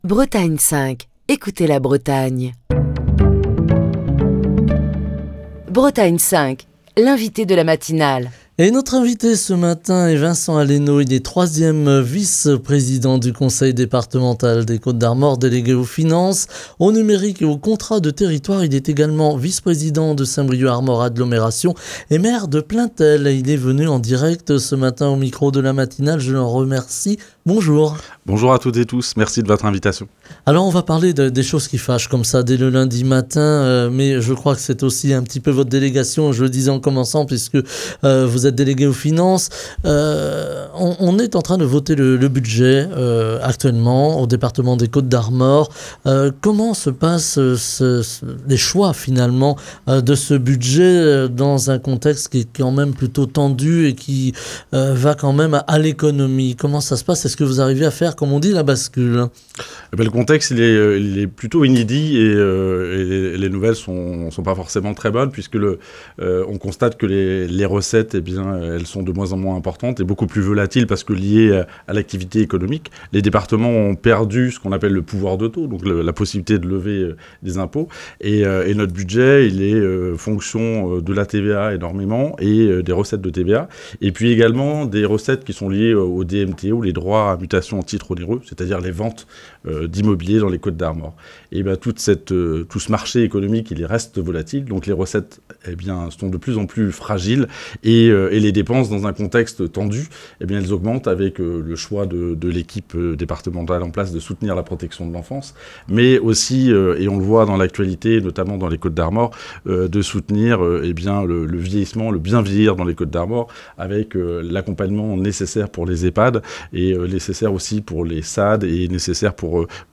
Nous en parlons ce lundi dans Bretagne 5 Matin avec notre invité, Vincent Alleno, 3ème vice-président du Conseil